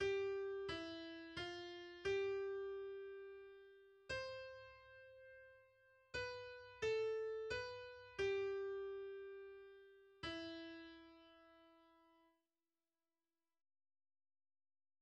Песня написана в размере 9